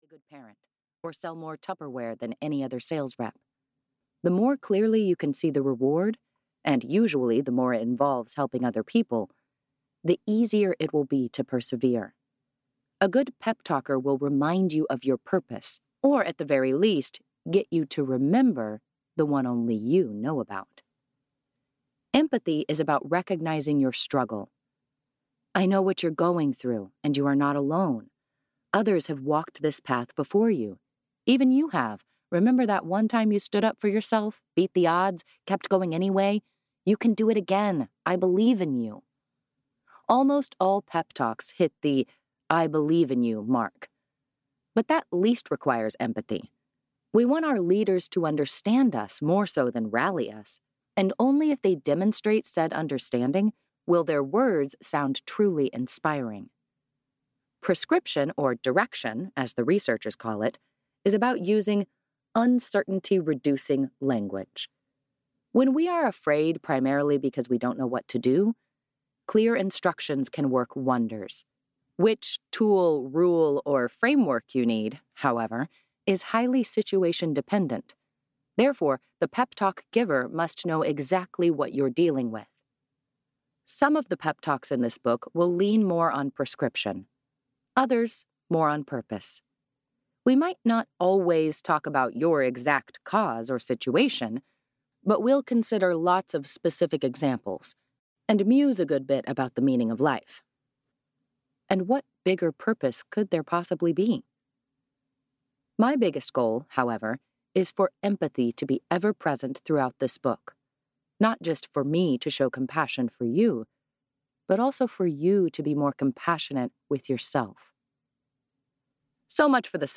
2-Minute Pep Talks audiokniha
Ukázka z knihy